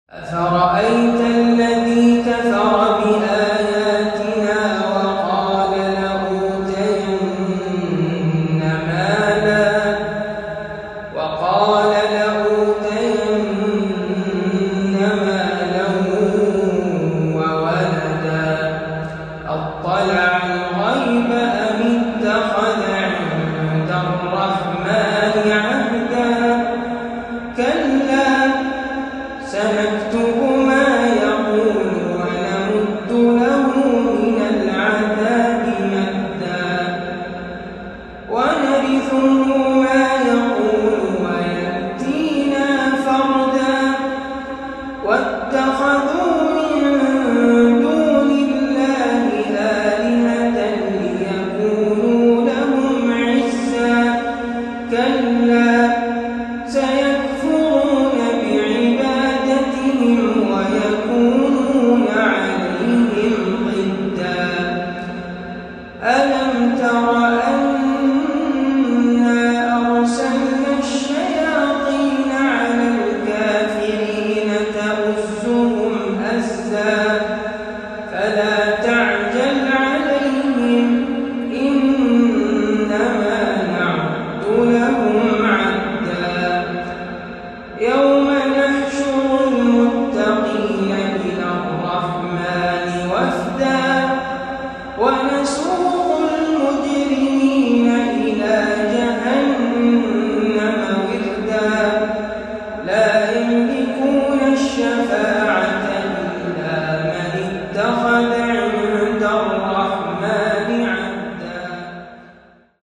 ما أعذبها من تلاوة ومن حسن الصوت
تلاوة من سورة مريم للقارئ